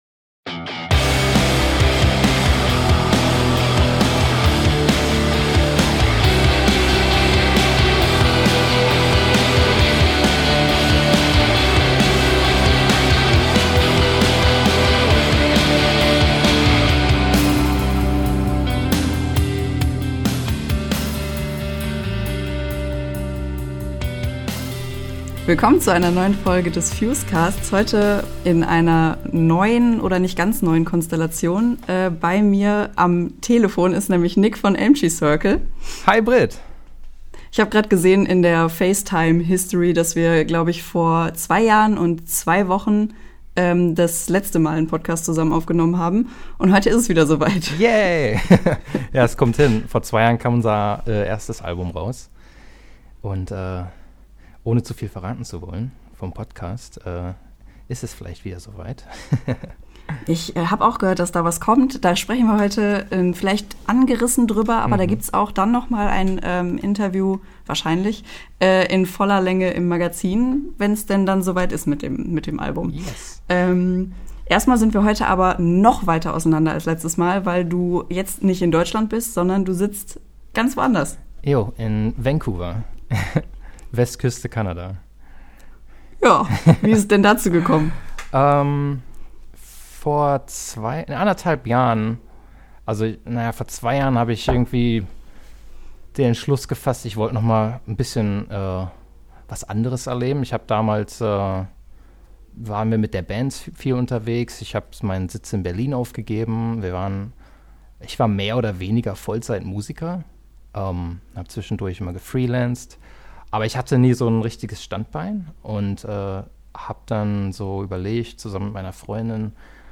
Letzte Episode Episode 78 – OH CANADA! Ein Gespräch mit ELM TREE CIRCLE 22.